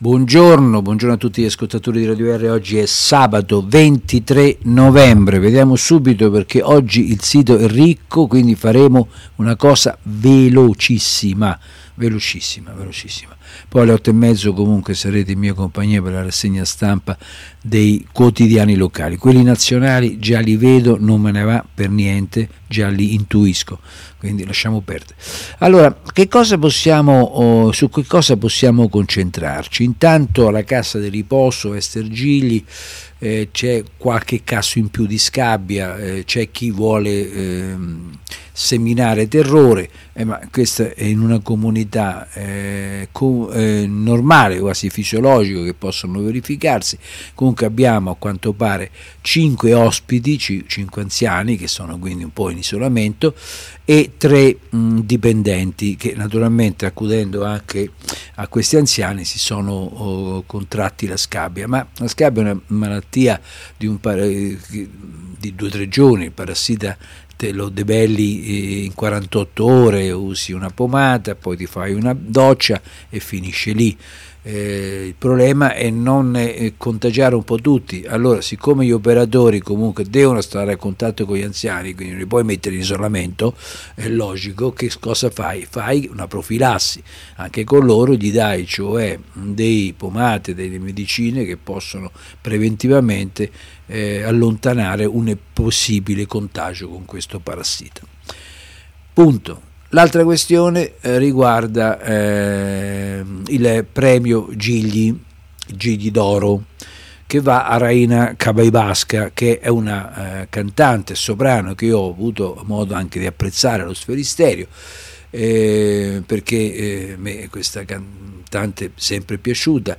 Rassegna Stampa
Stefano Miccini - consigliere comunale gruppo “Uniti per Recanati”.